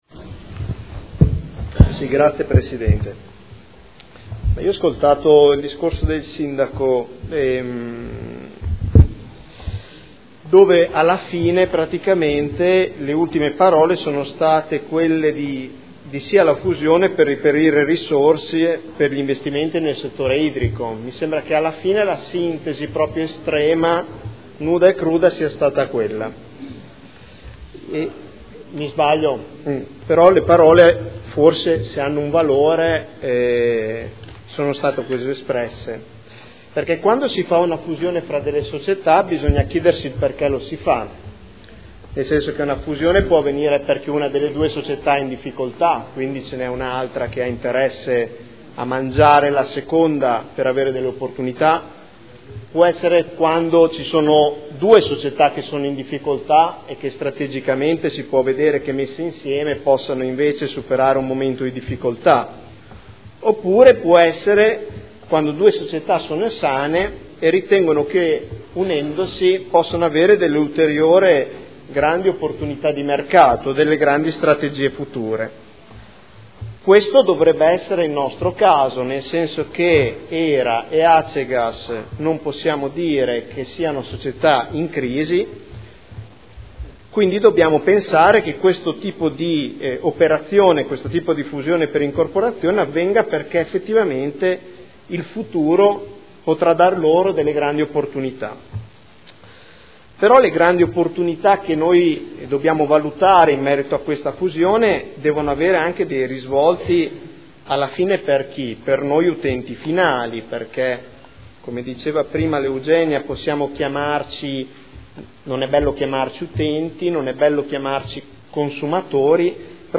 Nicola Rossi — Sito Audio Consiglio Comunale